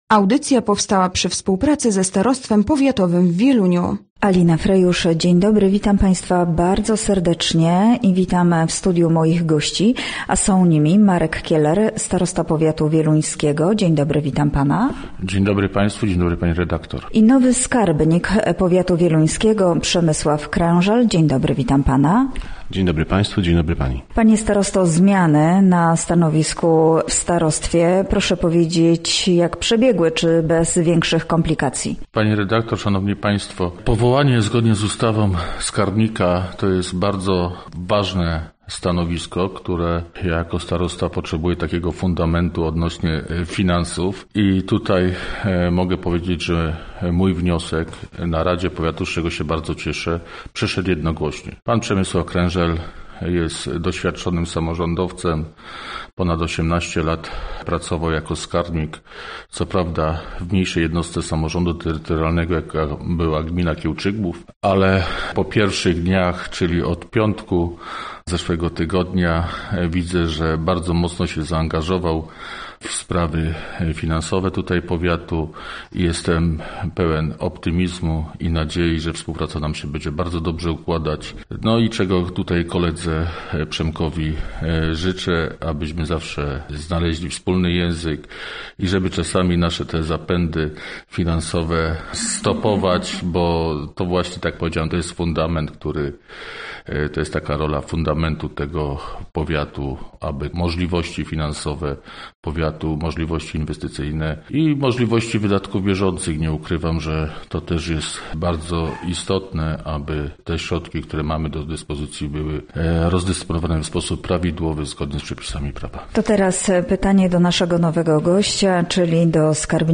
Gośćmi Radia ZW byli Marek Kieler, starosta wieluński